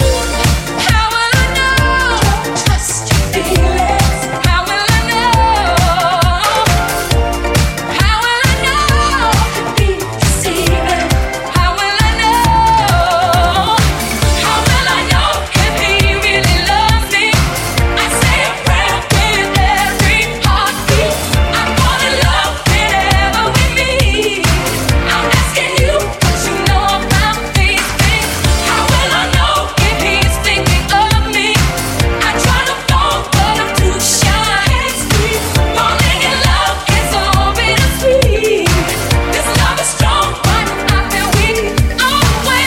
Genere: deep, dance, edm, club, remix